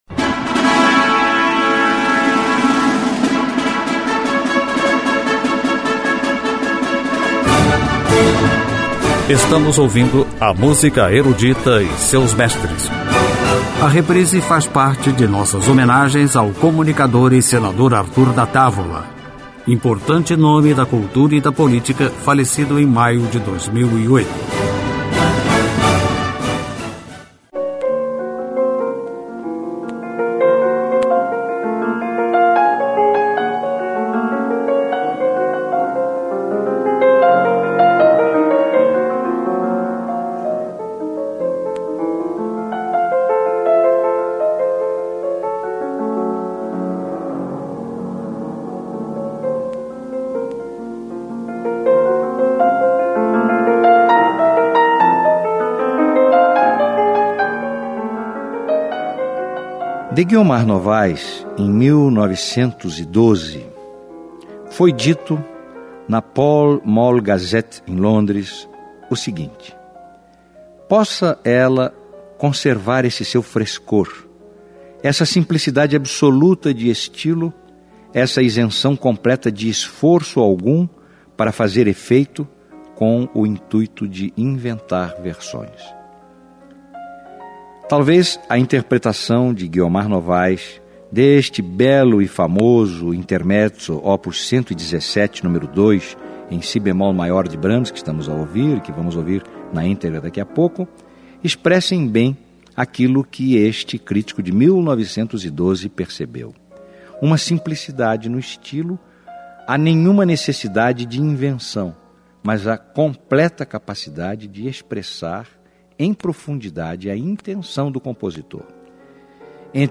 Música Erudita
Pianistas brasileiros